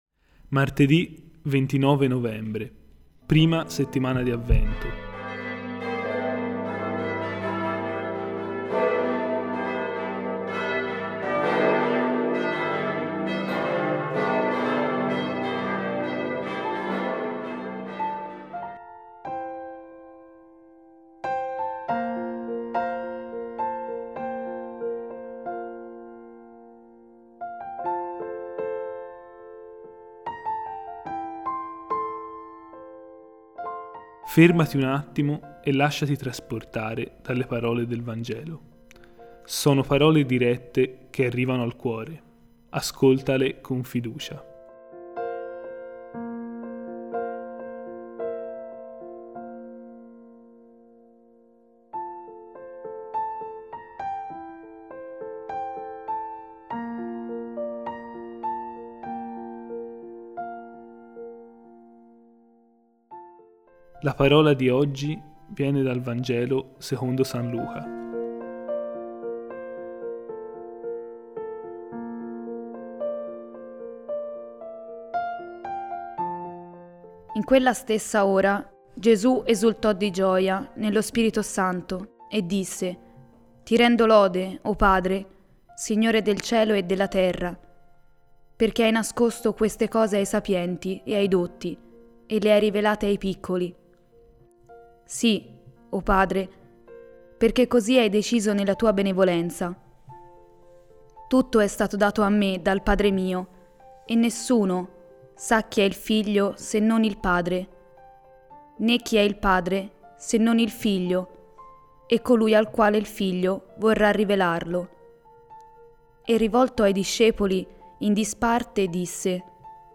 Musica: Draw me close to you – Piano COVER Worship Music – Michael W. Smith – The Katinas – Hillsong United